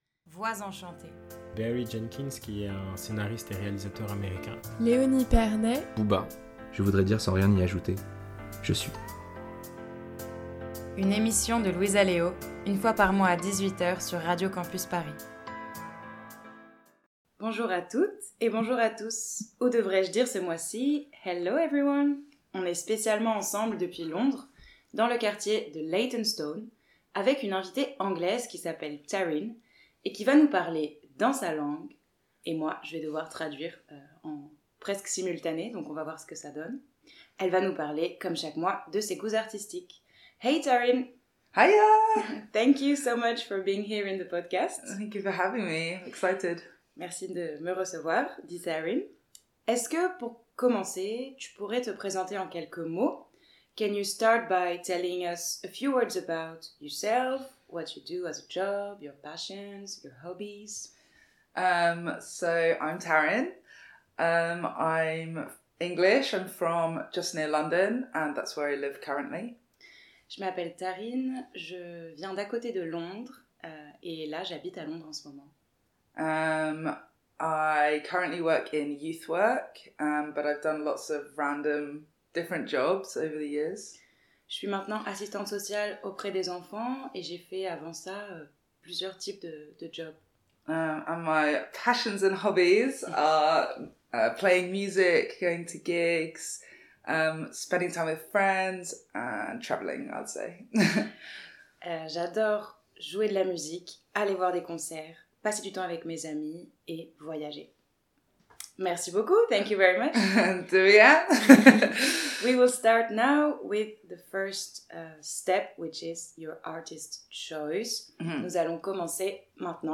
Type Entretien